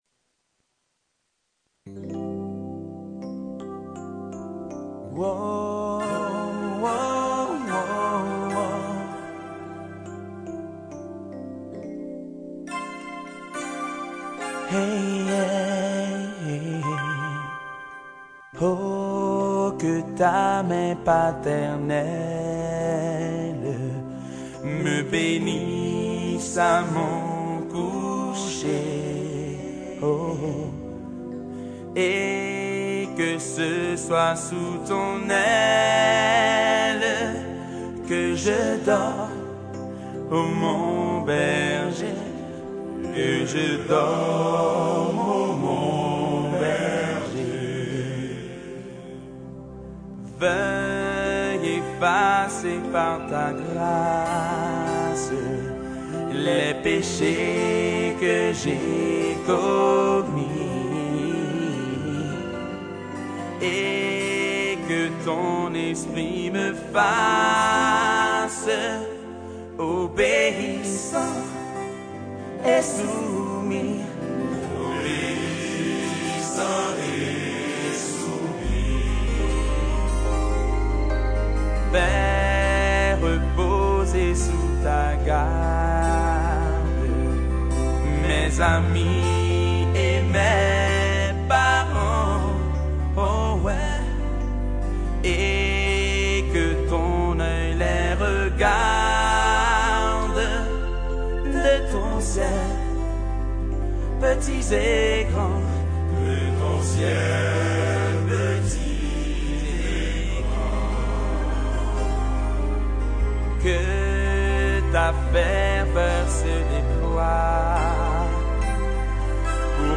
CHANTS D'ÉGLISE